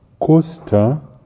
Naši kolegové z Ugandy nám nahráli správnou výslovnost vybraných jmen.